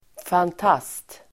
Uttal: [fant'as:t]